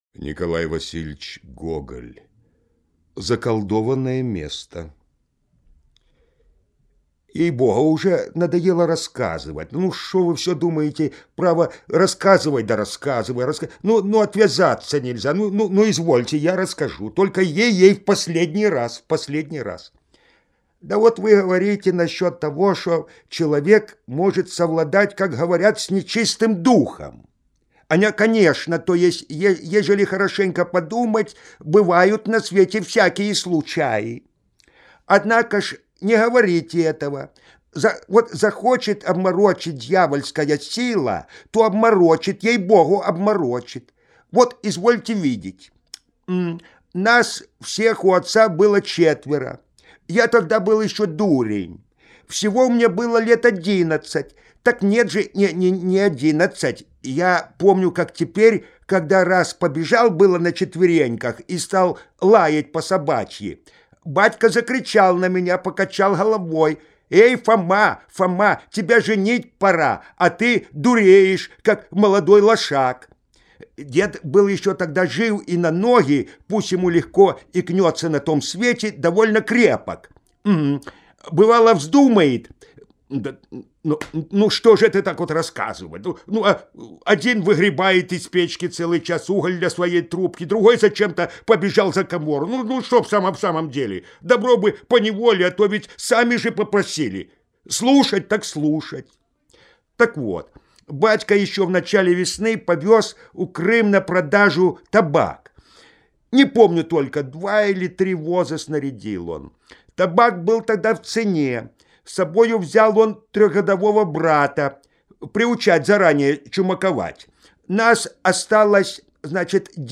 Заколдованное место - Гоголь - слушать рассказ онлайн